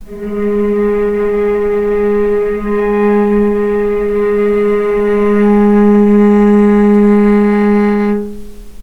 healing-soundscapes/Sound Banks/HSS_OP_Pack/Strings/cello/ord/vc-G#3-pp.AIF at 61d9fc336c23f962a4879a825ef13e8dd23a4d25
vc-G#3-pp.AIF